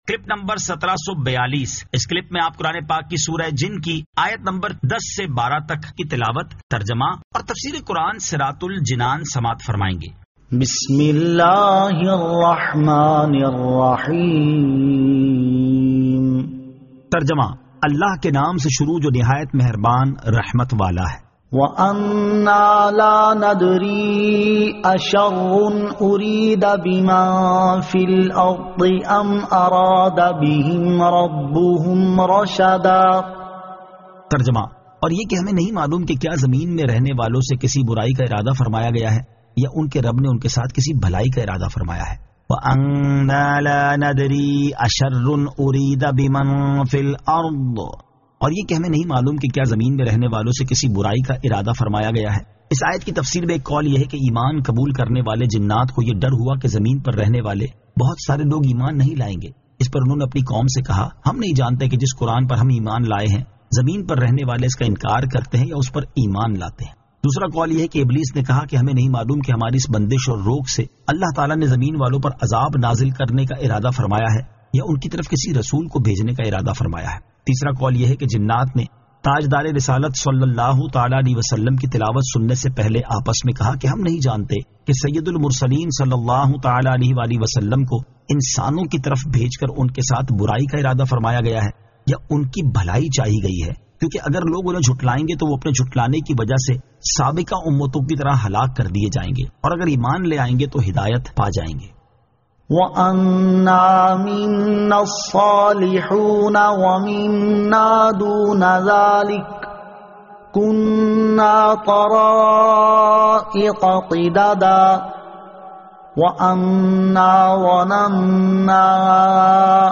Surah Al-Jinn 10 To 12 Tilawat , Tarjama , Tafseer